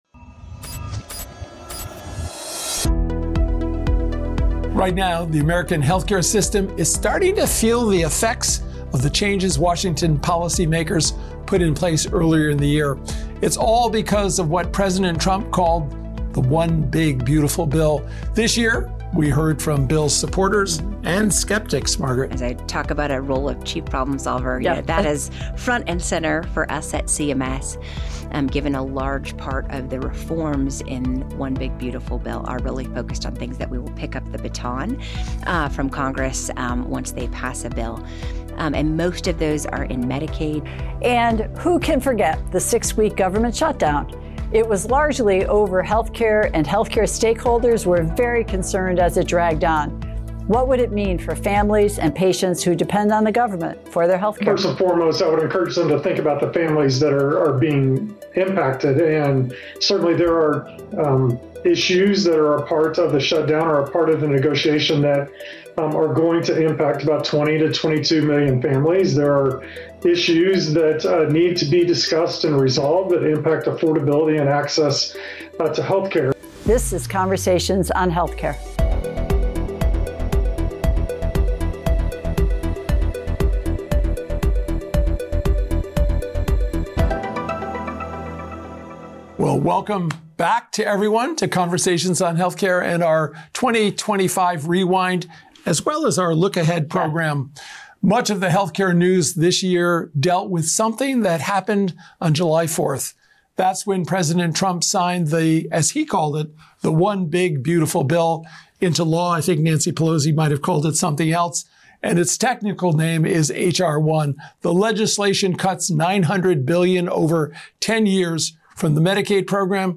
The conversation revisits the passage of H.R. 1, which was the largest Medicaid reduction in the program’s history, and the ripple effects states and families are already experiencing.